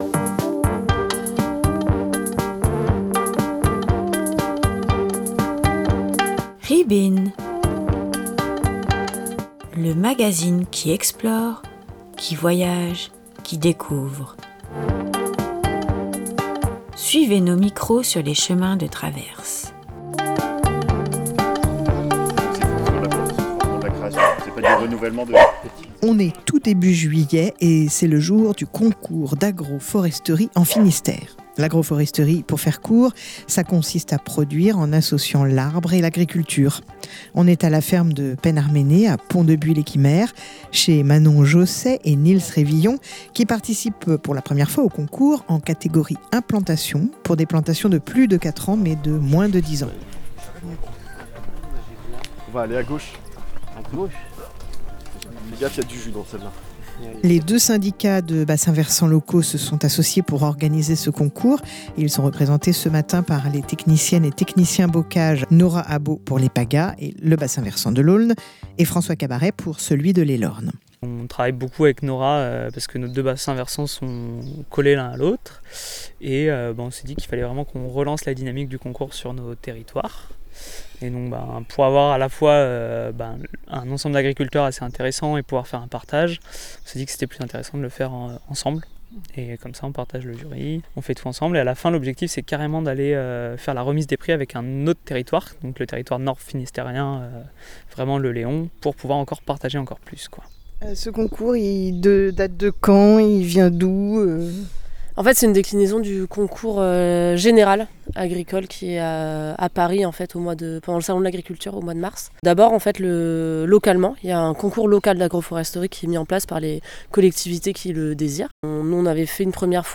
Reportage sur le concours d'agroforesterie 2025 du Finistère à la ferme de Penn ar Menez à Pont-de-Buis-lès-Quimerc'h
Visite à la ferme laitière bio de Penn ar menez, sur les hauteurs de Pont-de-Buis-lès-Quimerc'h, à l'occasion du concours d'agroforesterie de « L’Aulne et l’Elorn », organisé par les syndicats de bassin versant.